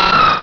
pokeemmo / sound / direct_sound_samples / cries / aron.wav